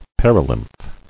[per·i·lymph]